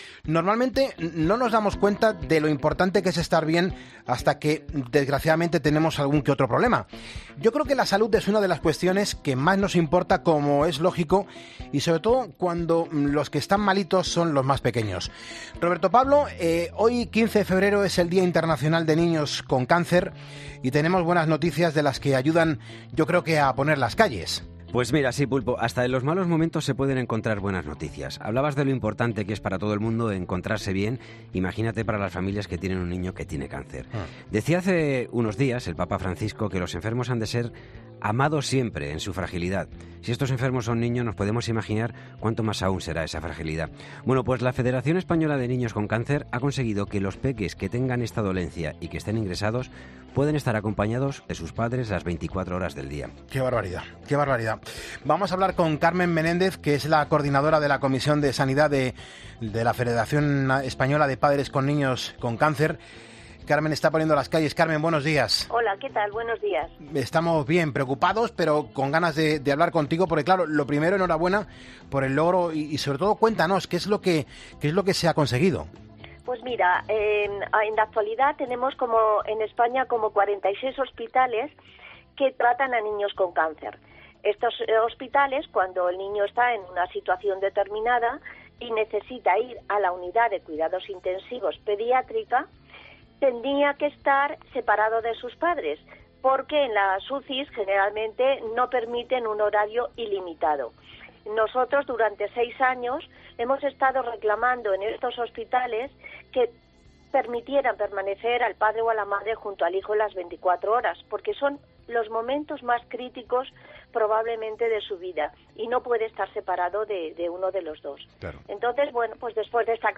LA ENTREVISTA COMPLETA